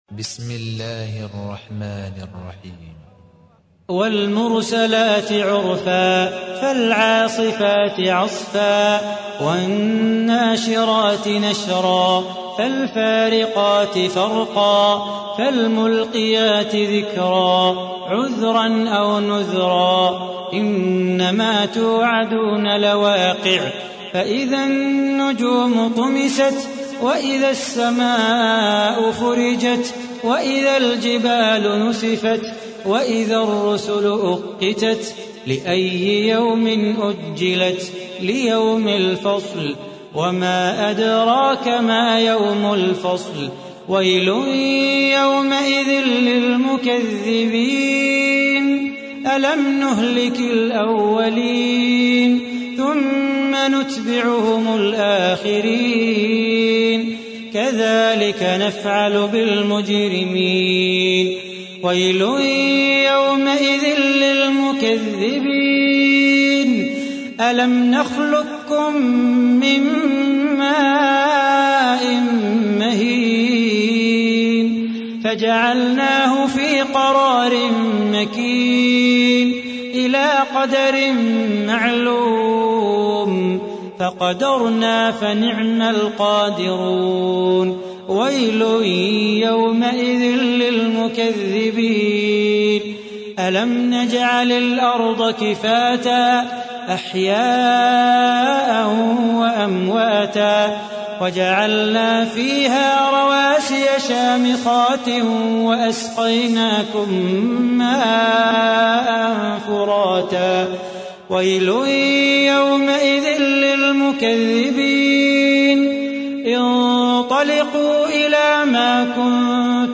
تحميل : 77. سورة المرسلات / القارئ صلاح بو خاطر / القرآن الكريم / موقع يا حسين